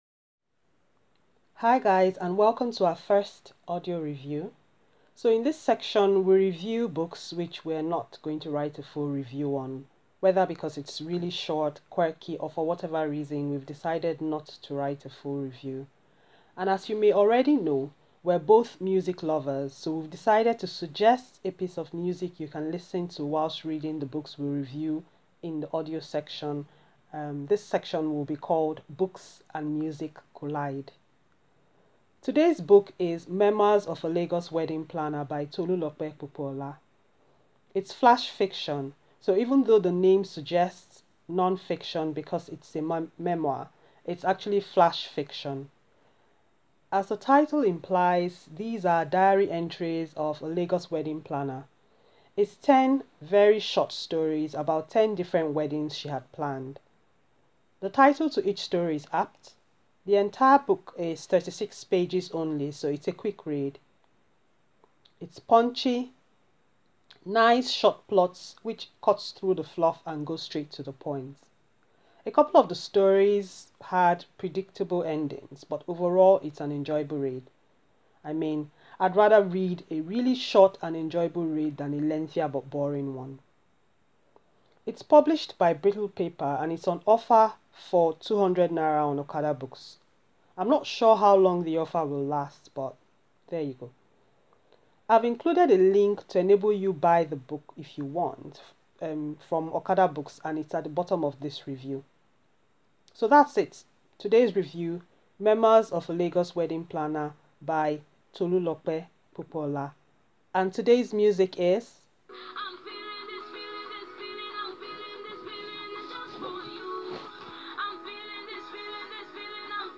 Review.